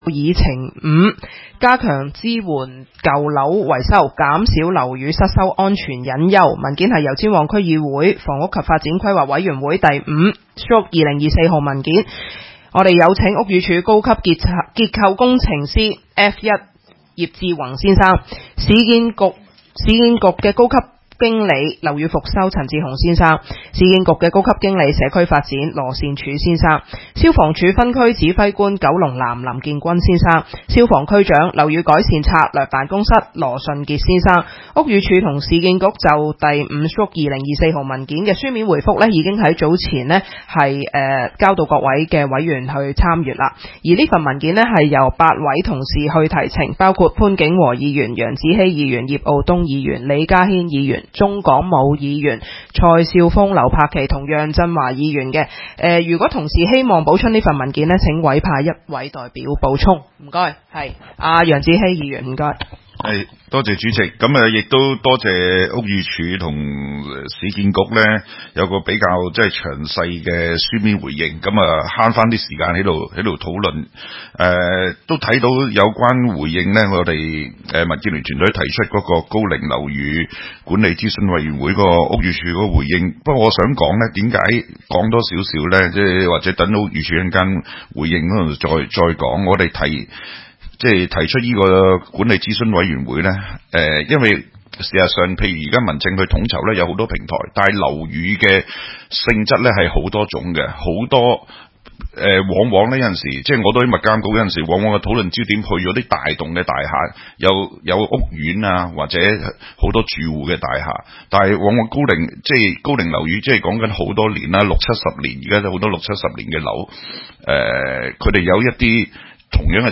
油尖旺區議會 - 委員會會議的錄音記錄